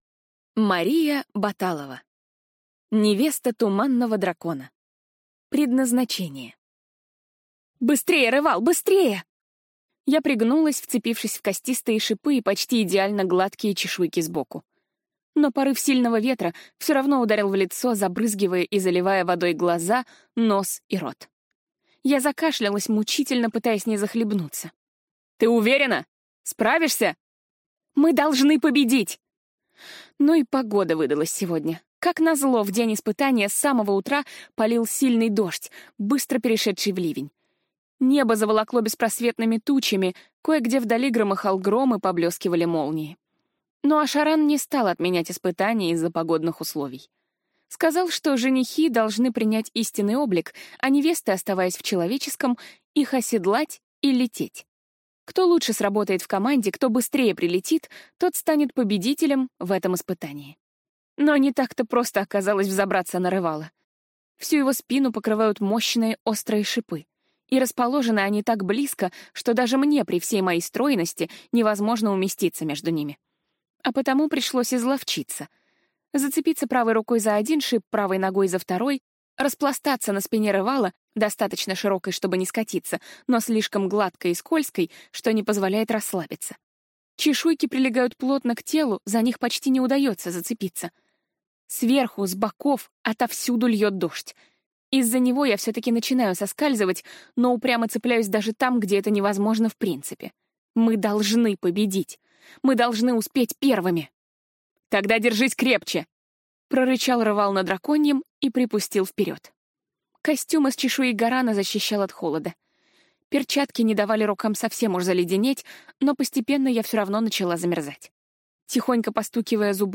Аудиокнига Невеста туманного дракона. Предназначение | Библиотека аудиокниг